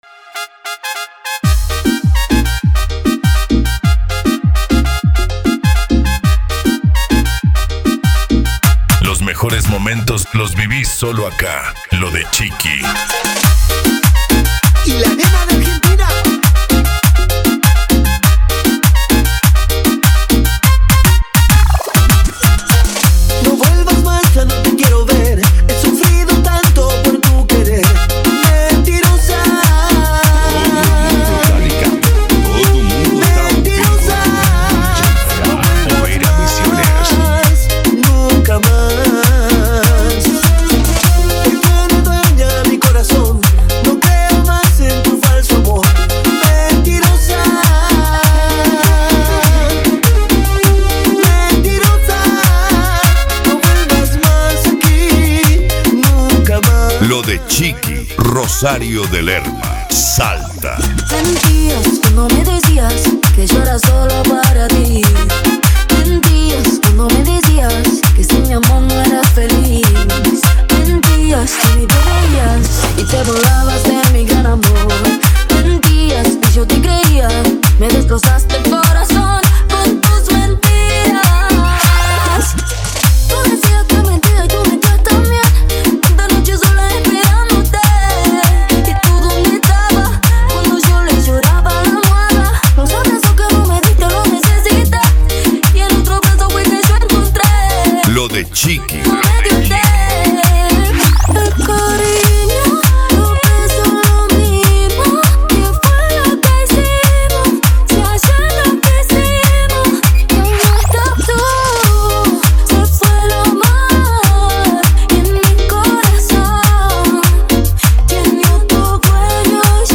Remix
Retro Music